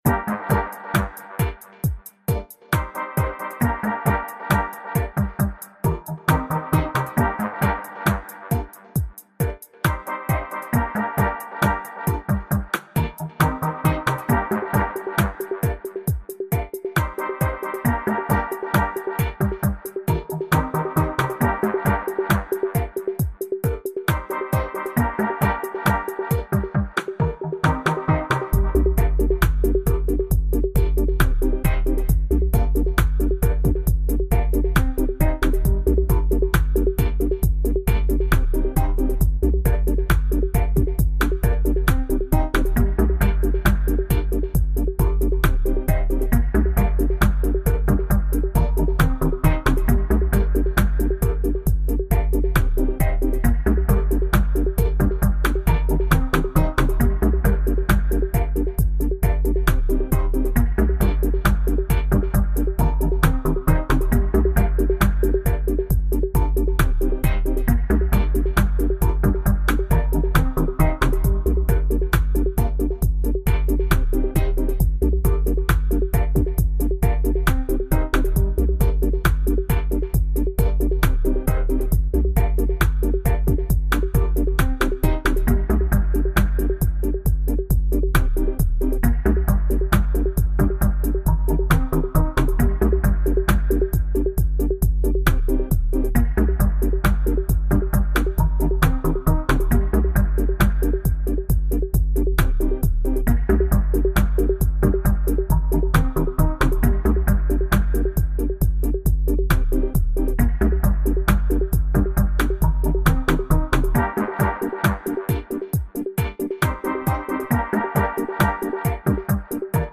Dubplate